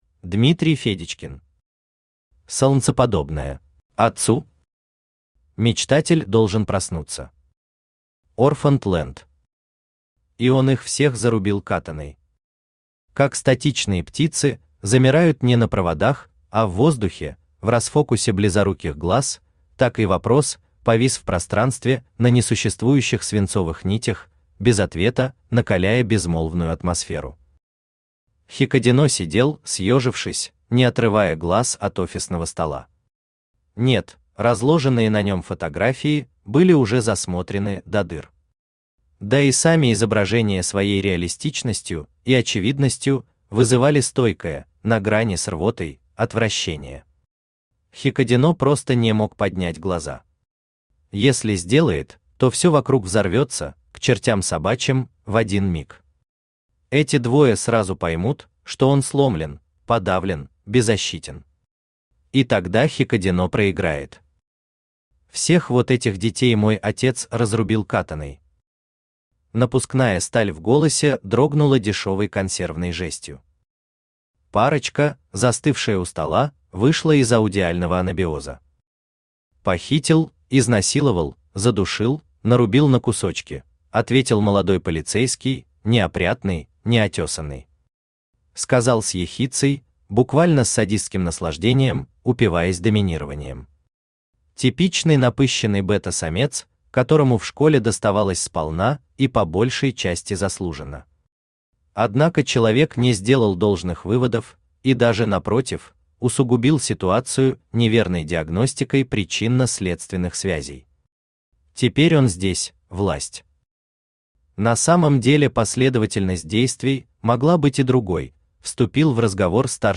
Аудиокнига Солнцеподобное | Библиотека аудиокниг
Aудиокнига Солнцеподобное Автор Дмитрий Федечкин Читает аудиокнигу Авточтец ЛитРес.